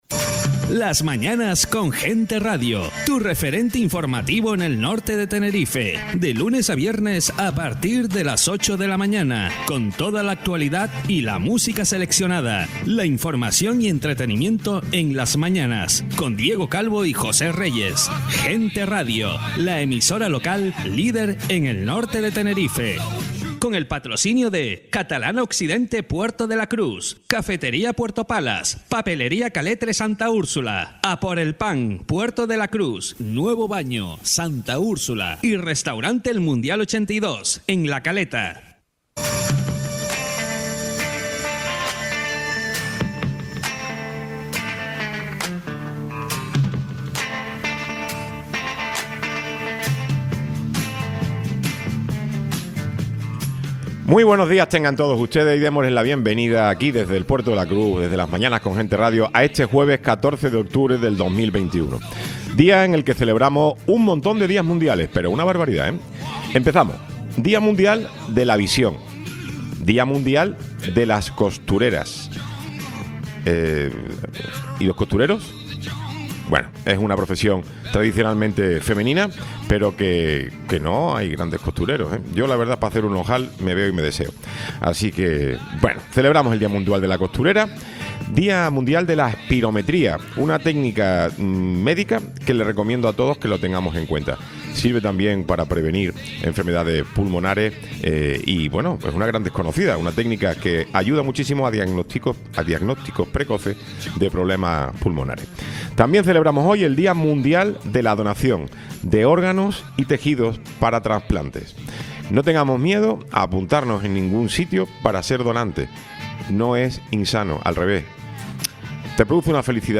Tiempo de entrevista